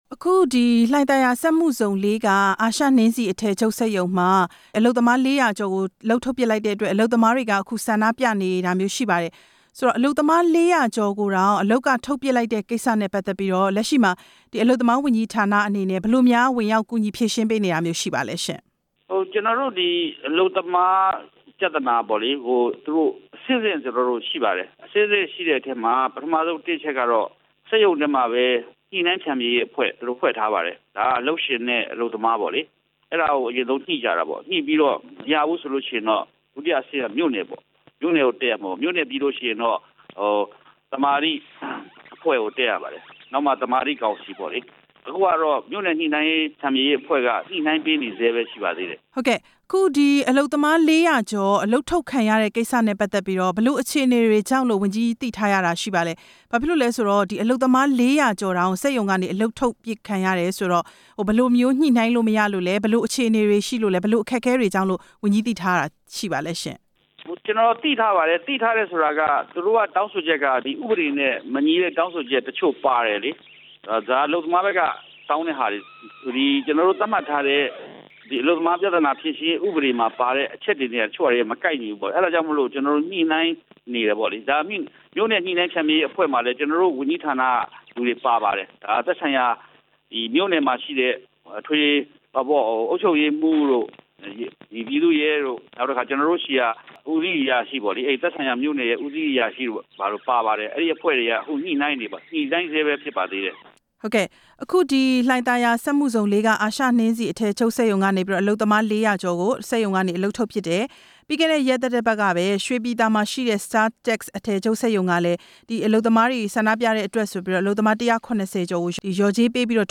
အလုပ်သမားဝန်ကြီးဌာန ဒု-ဝန်ကြီး နဲ့ ဆက်သွယ်မေးမြန်းချက် နားထောင်ရန်